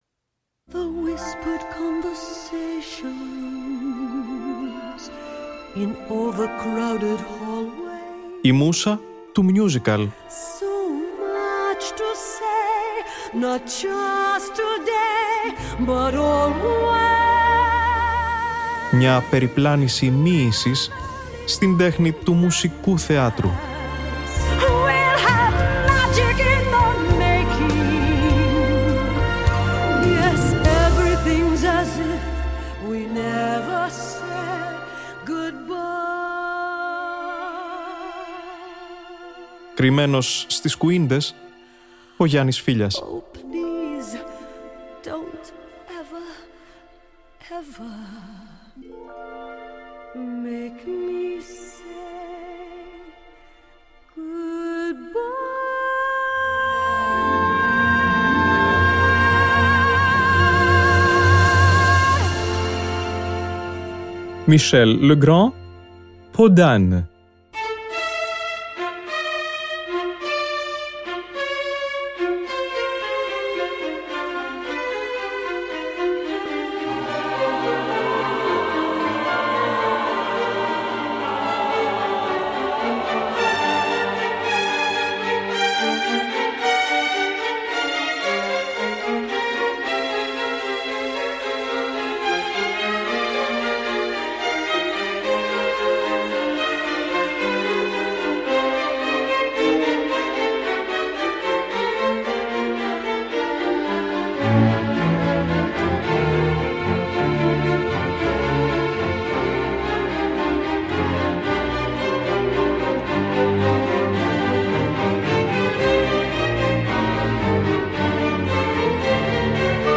original soundtrack
γαλλικό κινηματογραφικό musical